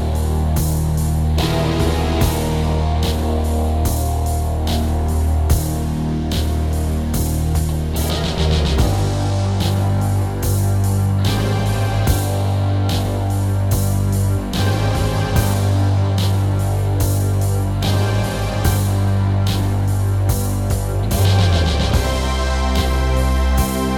One Semitone Down Rock 6:30 Buy £1.50